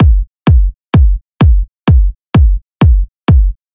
ac 128 bpm house drums 01 kick
描述：这些循环是在FL Studio 12中创建的，并进行了干式处理。
Tag: 128 bpm House Loops Drum Loops 646.17 KB wav Key : Unknown